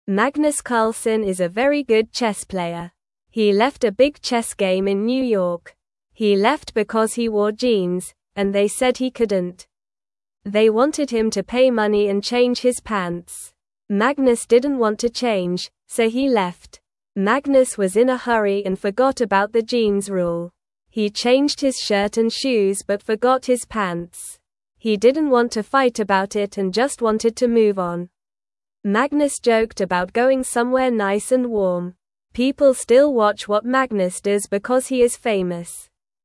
Normal
English-Newsroom-Beginner-NORMAL-Reading-Magnus-Carlsen-Leaves-Chess-Game-Over-Jeans-Rule.mp3